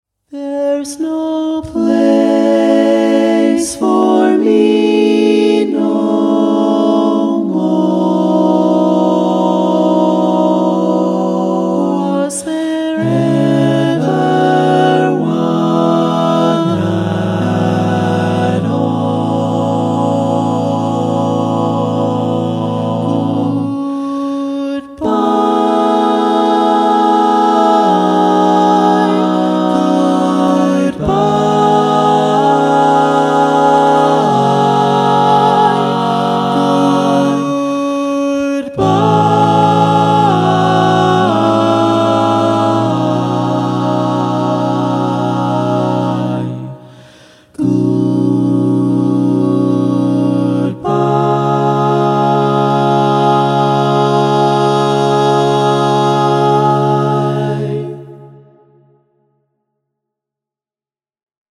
Key written in: C# Minor
How many parts: 4
Type: Barbershop
All Parts mix: